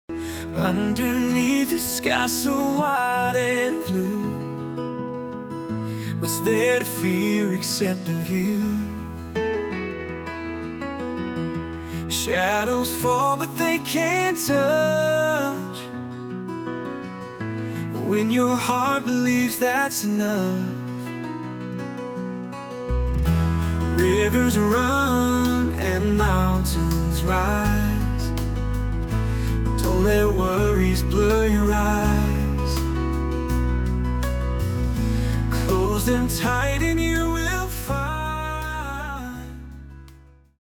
Short version of the song, full version after purchase.
An incredible Country song, creative and inspiring.